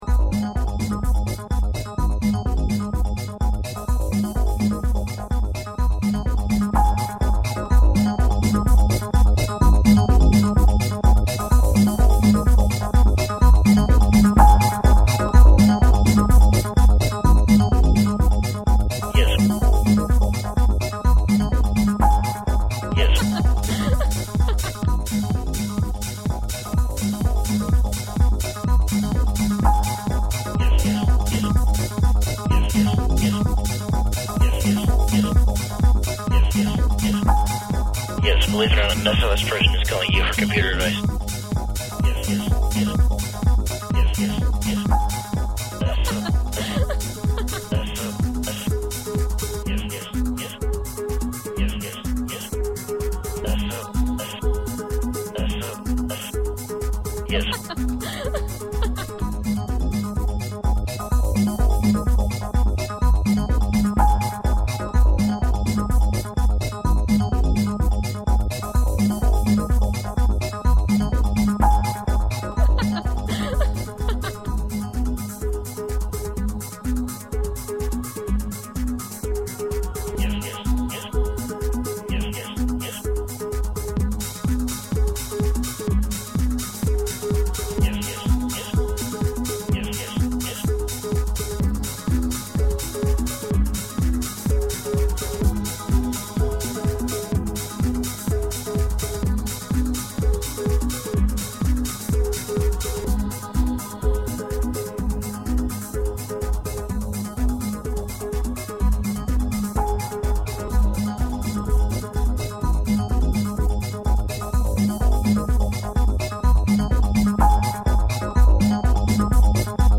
SOS This was another project in the same class, but this one used recorded samples of no longer than 10 seconds, combined, looped, or edited to our taste.